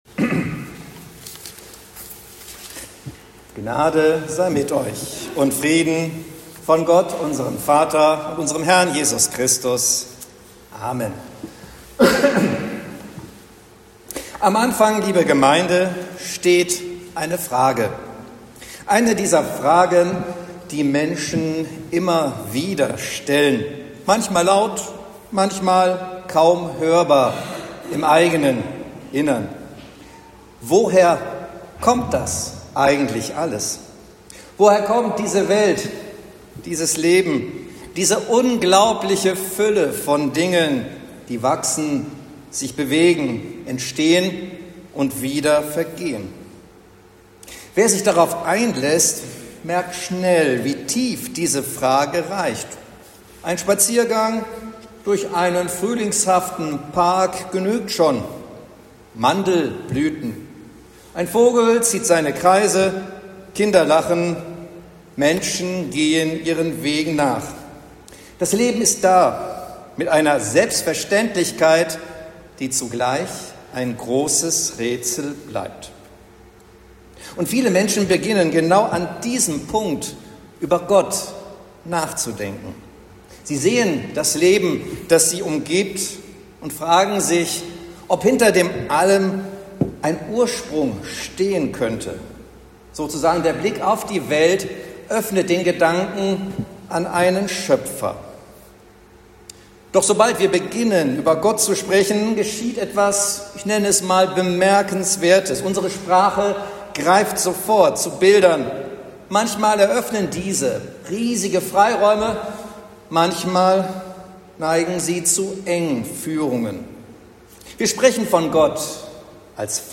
Predigt zu Lätare